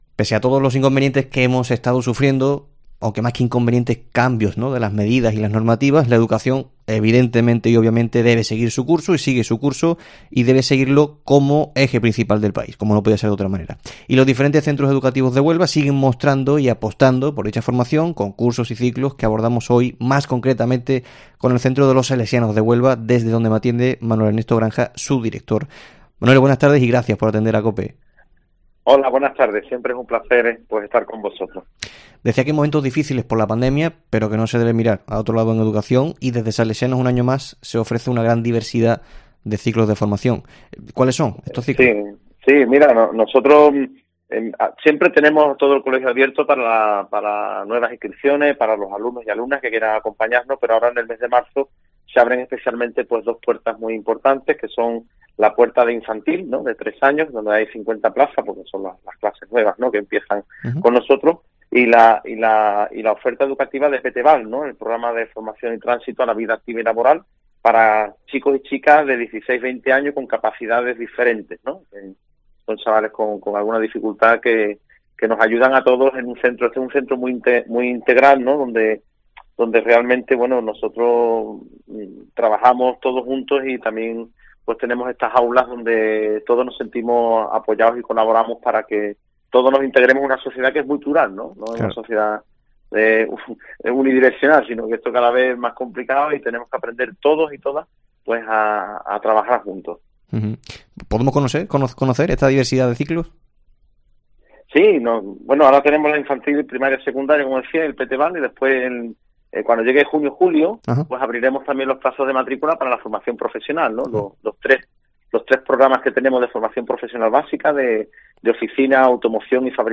En el Mediodía COPE Huelva de este martes hemos hablado